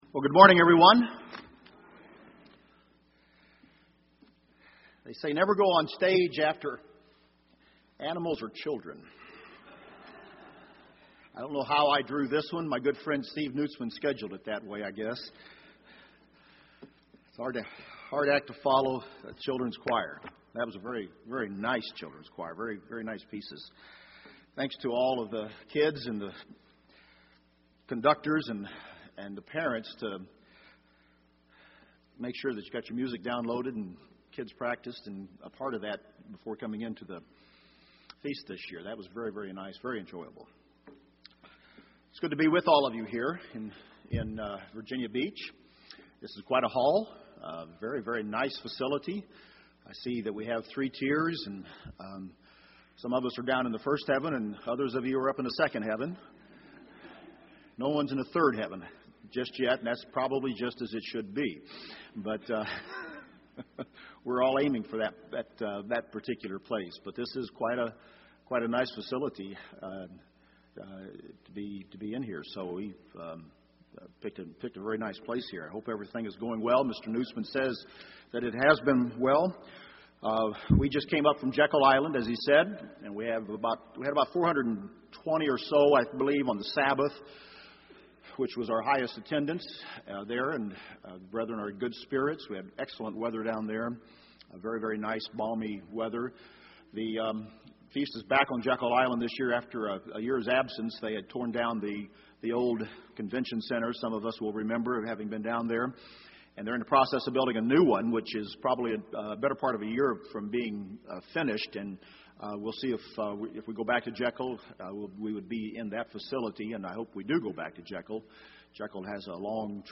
This sermon was given at the Virginia Beach, Virginia 2011 Feast site.